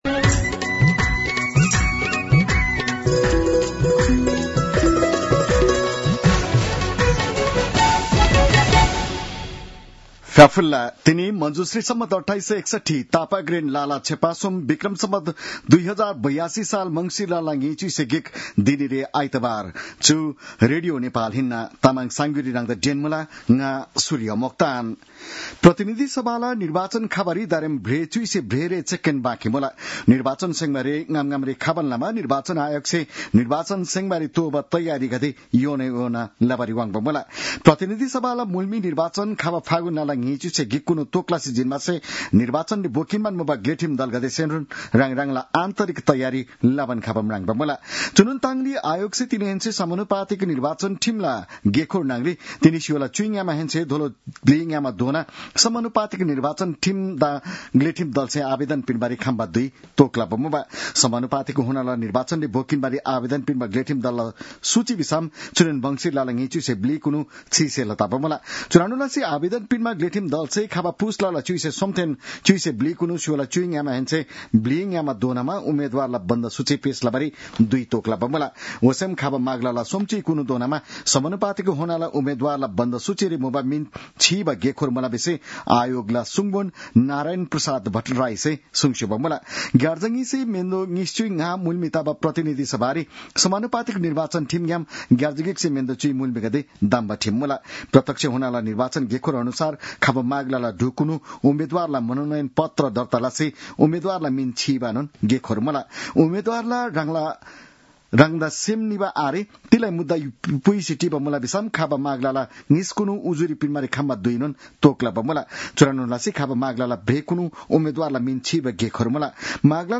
तामाङ भाषाको समाचार : २१ मंसिर , २०८२